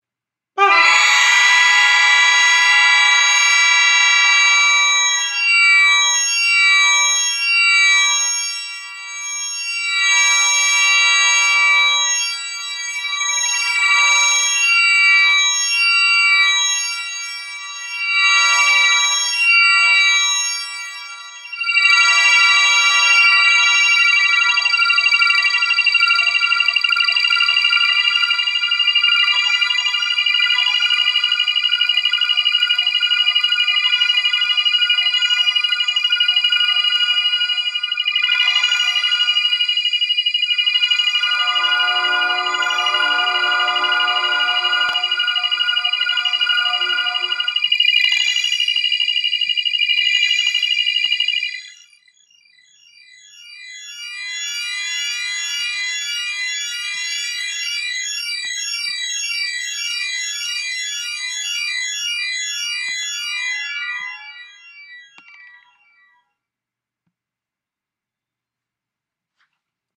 Here's the multi-shifter in a feedback loop :-)